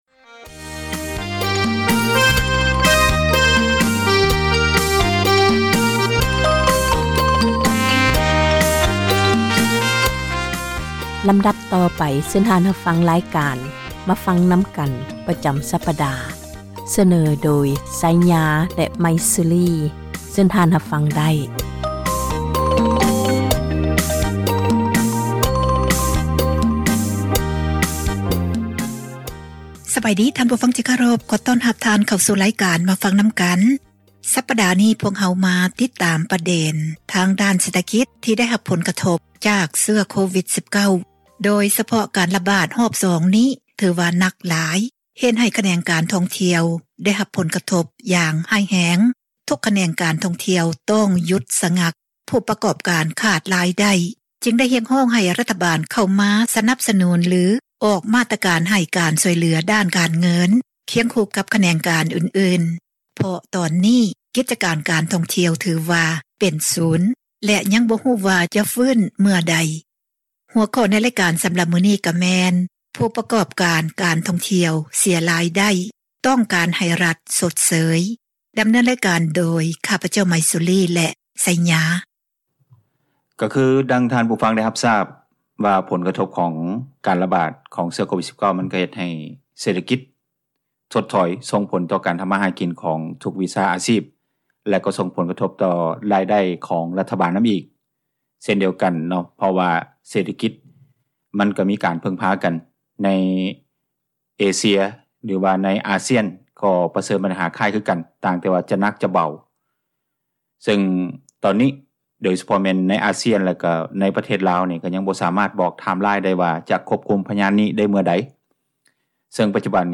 ການສົນທະນາ ໃນບັນຫາ ແລະ ຜົລກະທົບຕ່າງໆ ທີ່ເກີດຂຶ້ນ ຢູ່ປະເທດລາວ.